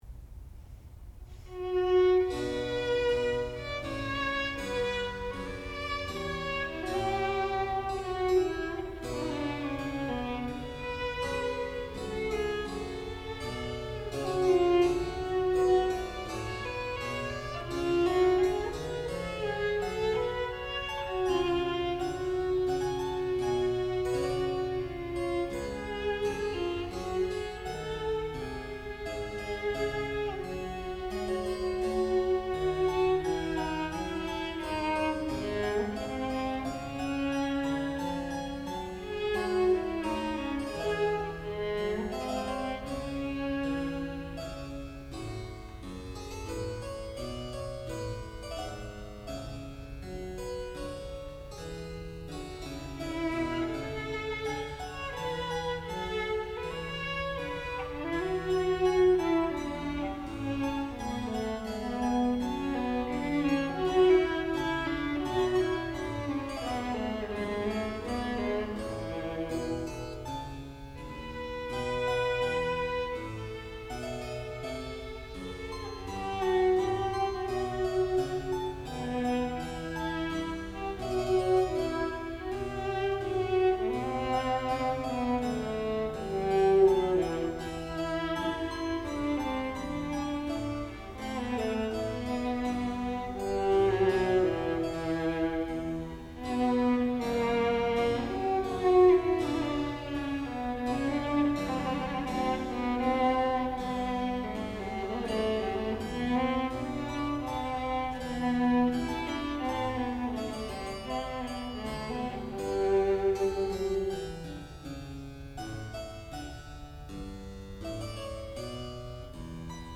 sound recording-musical
classical music
harpsichord
Master's Recital
viola